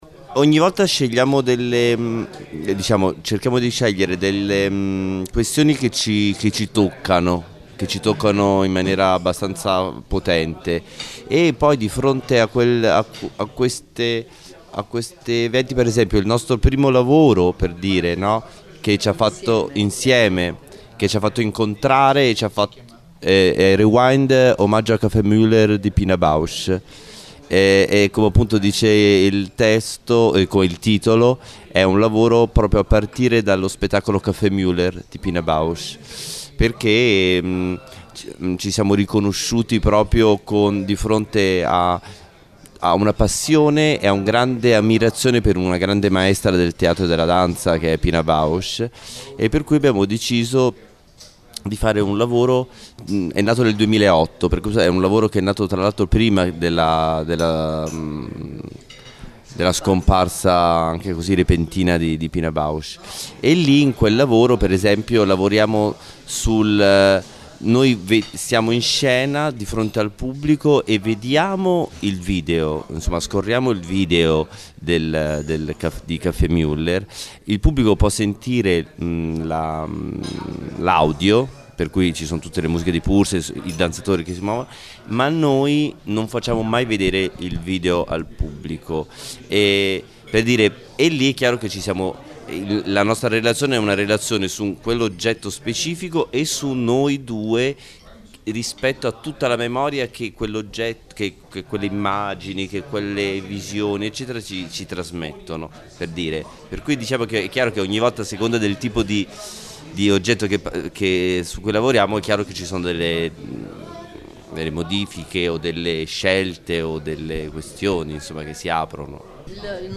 Audio : original de l’interview en italien.